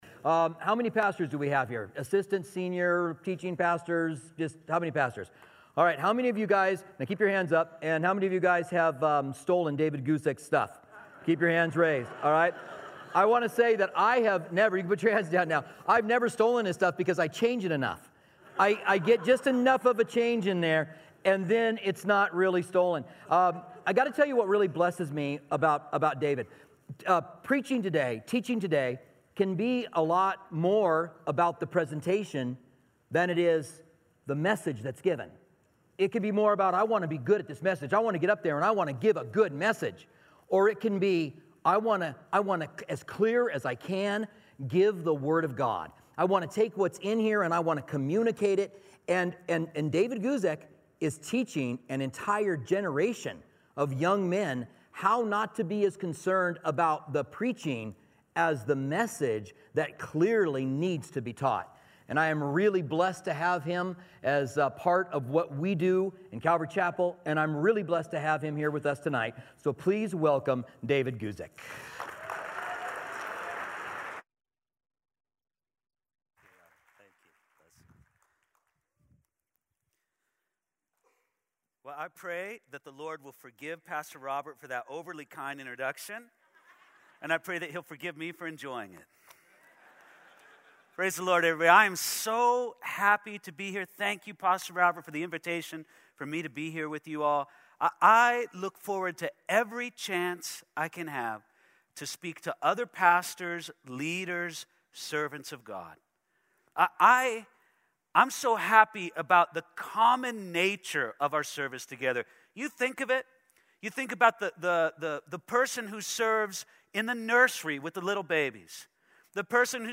at the 2018 Southwest Pastors and Leaders Conference